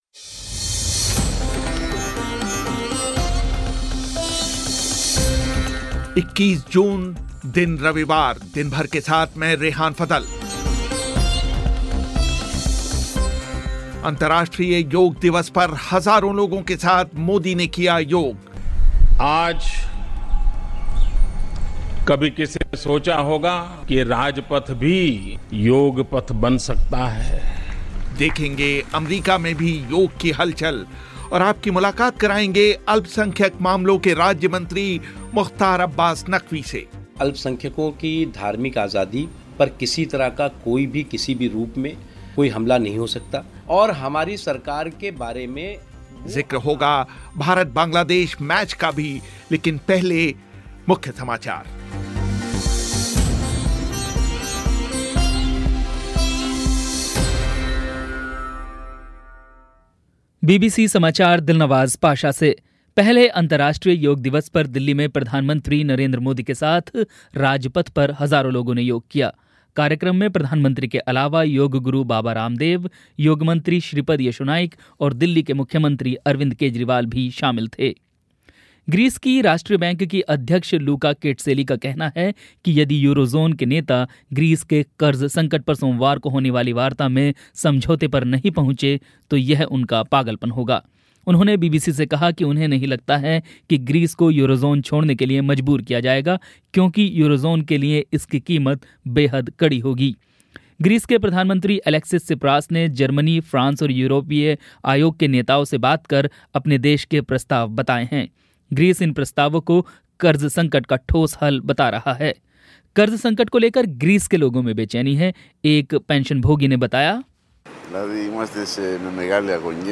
अंतर्राष्ट्रीय योग दिवस पर हज़ारों लोगों के साथ नरेंद्र मोदी ने किया योग - अमरीका में भी योग की हलचल - मुलाकात अल्पसंख्यक मामलों के राज्यमंत्री मंत्री मुख़्तार अब्बास नक़वी से